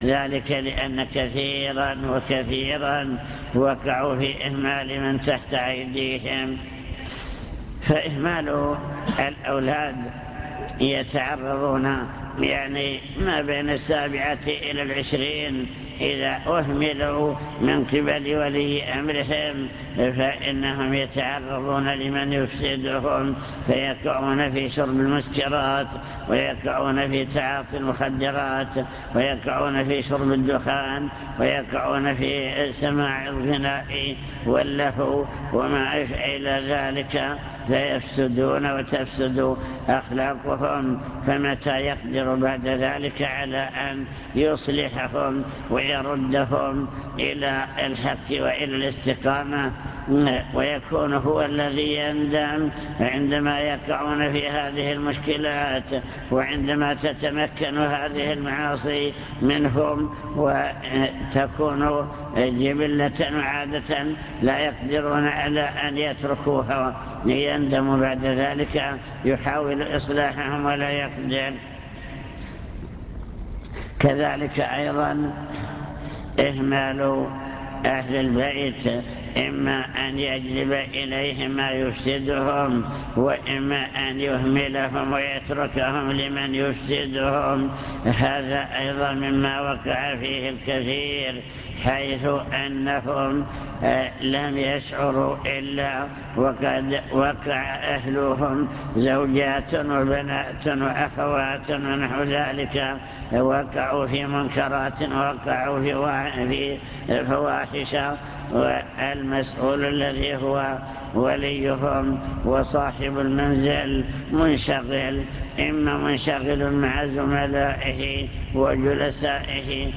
المكتبة الصوتية  تسجيلات - لقاءات  عوامل صلاح المجتمع (لقاء مفتوح)